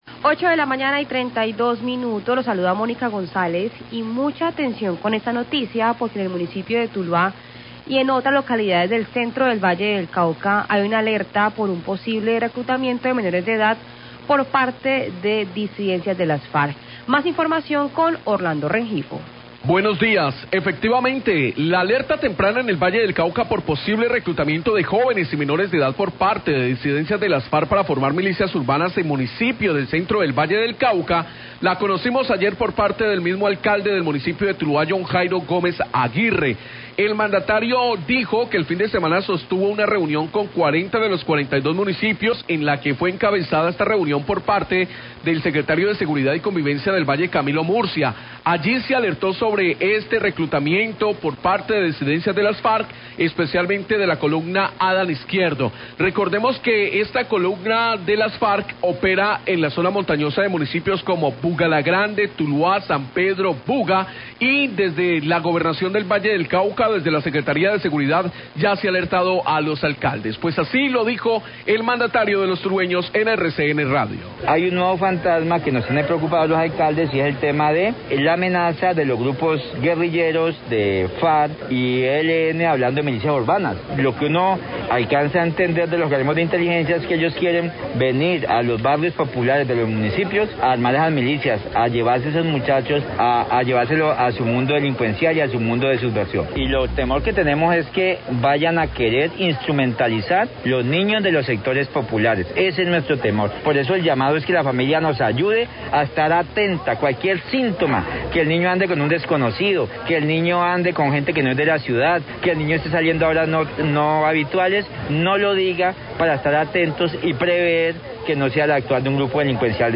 Radio
El Alcalde de Tuluá, John Jairo Gómez, habla de la alerta temprana en el centro del Valle por el posible reclutamiento de menores por parte de las disidencias de las FARC, del grupo Adán Izquierdo, para convertirlos en milicias urbanas.